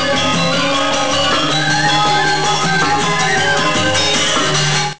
Gamelan Music
The crisply percussive gamelan music which accompanies the dancers, shares their dynamism and agility.